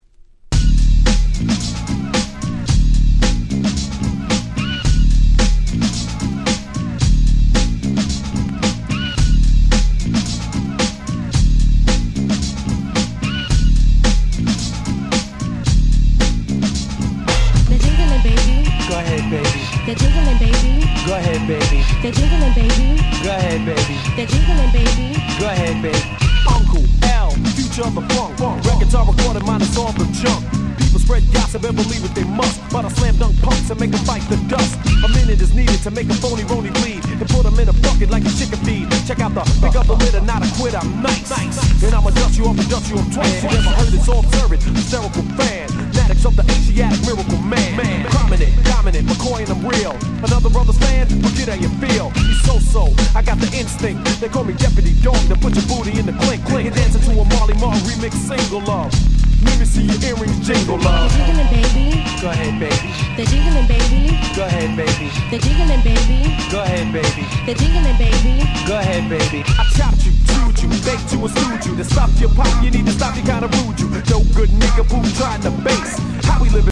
本盤は90'sの人気Hip Hop Classicsばかりを全6曲収録！！